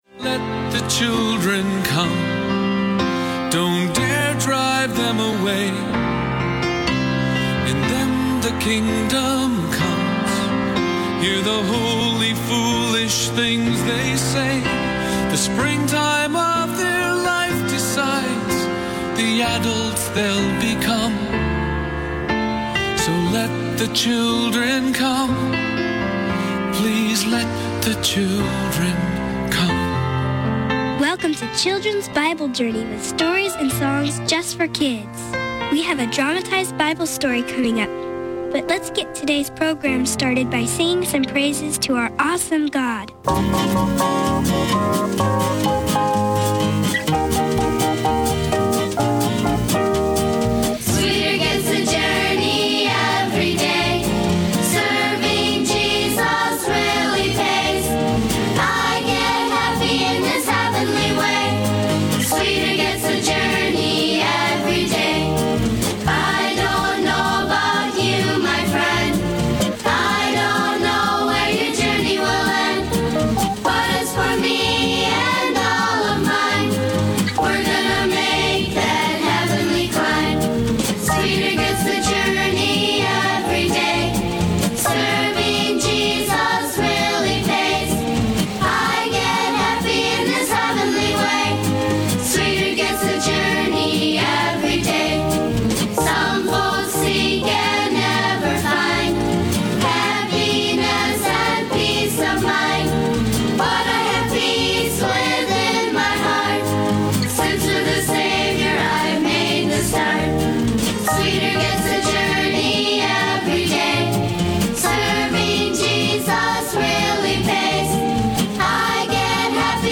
Enjoy a variety of programs for kids in less than 30 minutes.